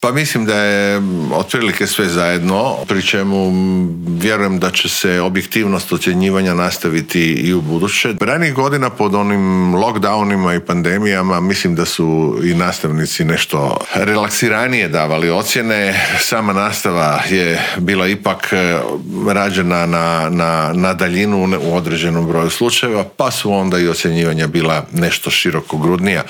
ZAGREB - U ponedjeljak će zvono označiti početak nove školske godine, a prije nego što se školarci vrate pred ploču, pred mikrofon Media servisa u Intervjuu tjedna stao je ministar znanosti i obrazovanja Radovan Fuchs.